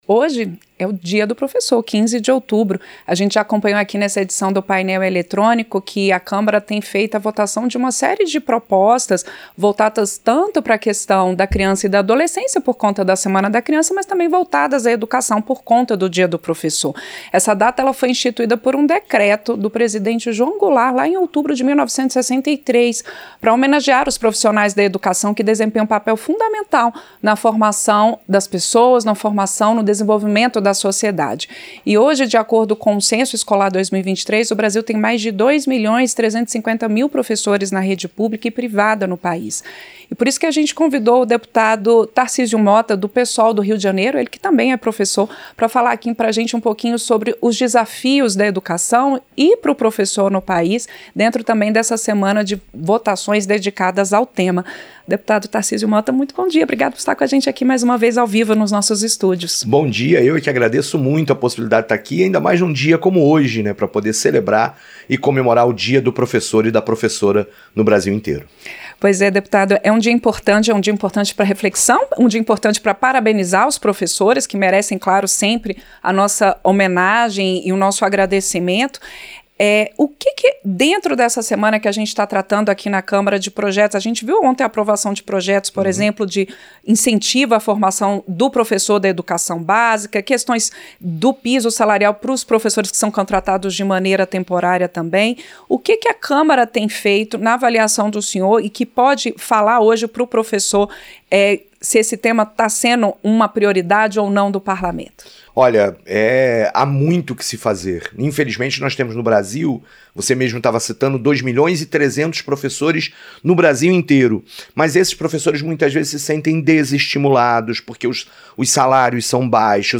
Entrevista - Dep. Tarcísio Motta (PSOL-RJ)